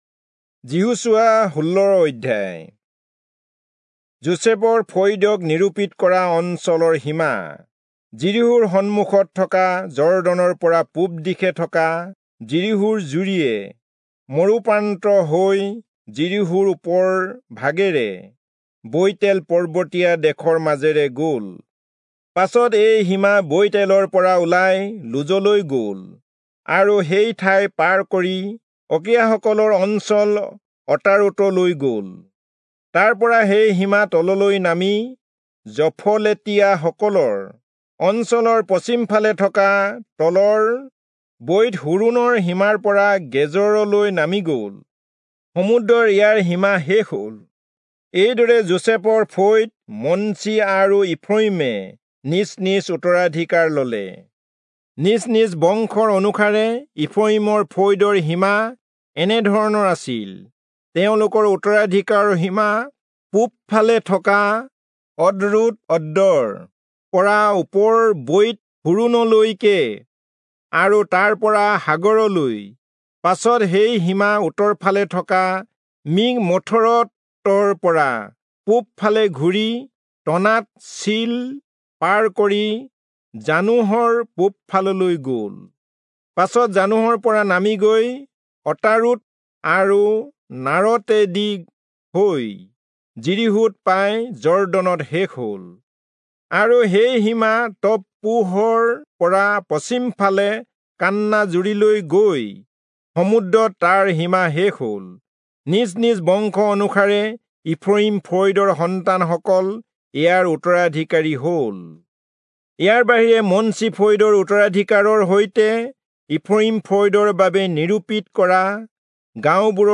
Assamese Audio Bible - Joshua 1 in Bhs bible version